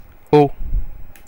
Prononciation
Prononciation France (Paris): IPA: /o/